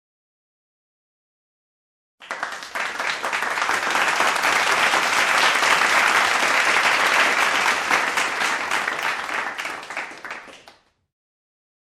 Clapping Crowd - Botón de Efecto Sonoro